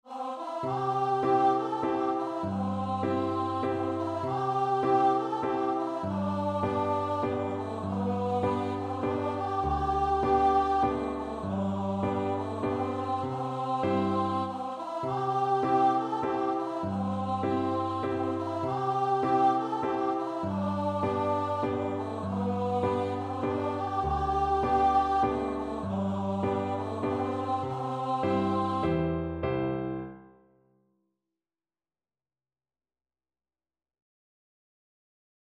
3/4 (View more 3/4 Music)
Traditional (View more Traditional Voice Music)